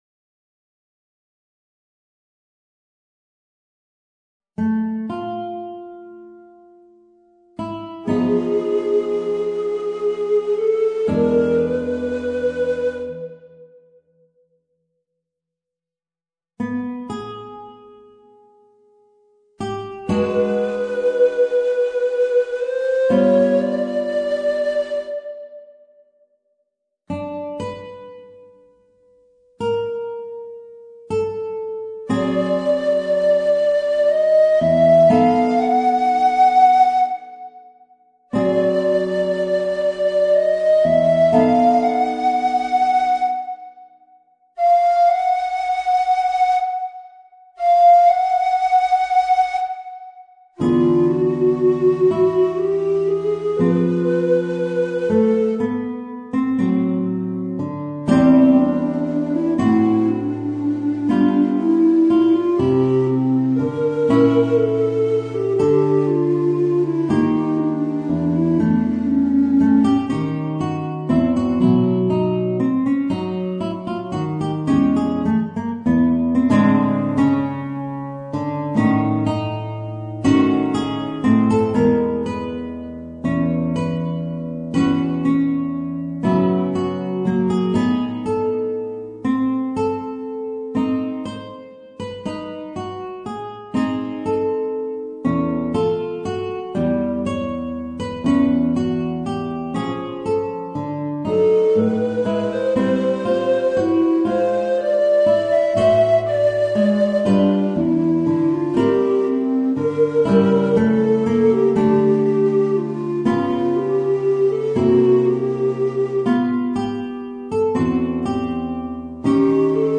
Voicing: Soprano Recorder and Guitar